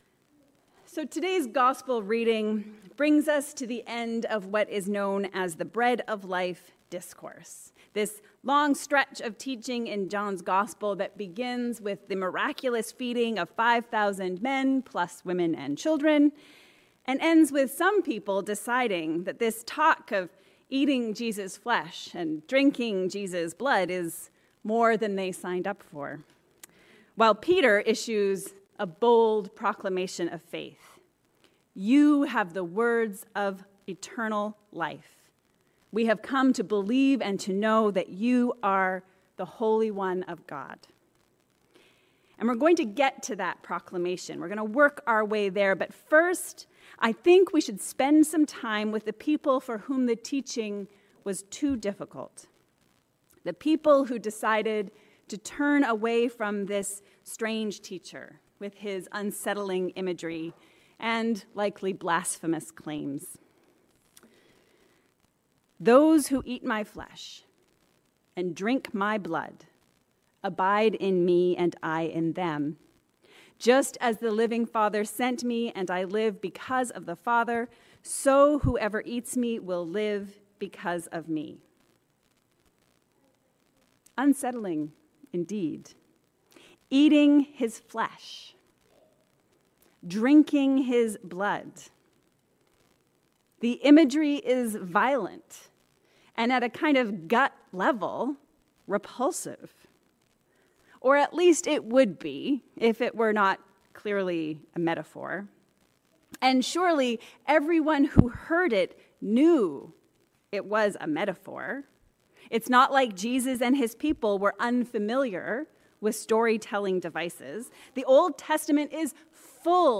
Called to be the Body of Christ. A Sermon for the 14th Sunday after Pentecost.